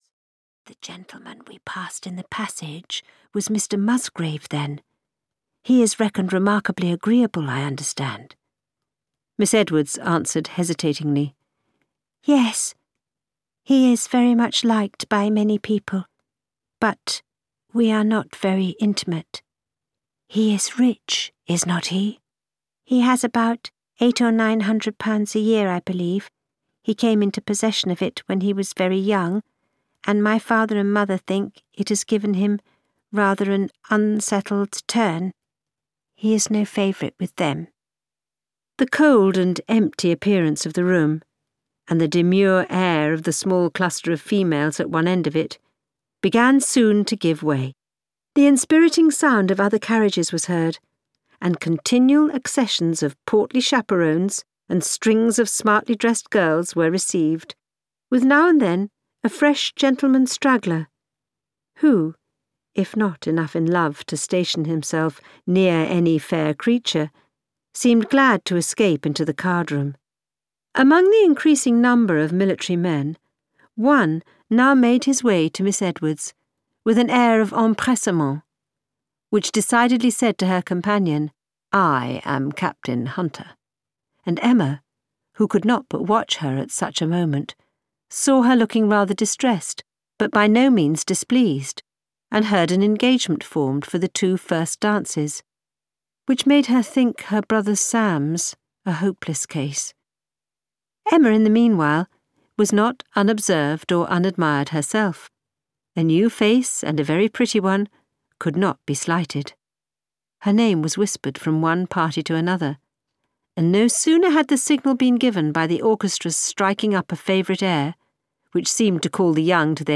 The Watsons, Sanditon (EN) audiokniha
Ukázka z knihy